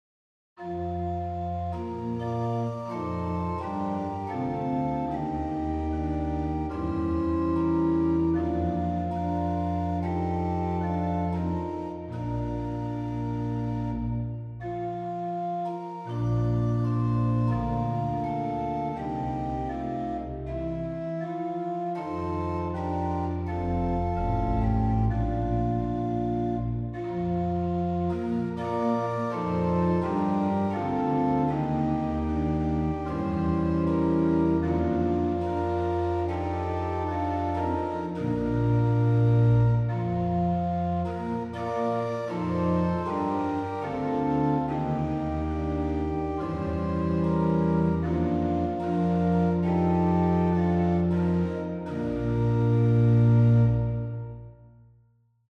Posnetki z(+) in brez uvoda
in so obrezani za ponavljanje / loop / kitice